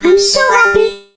nani_lead_vo_01.ogg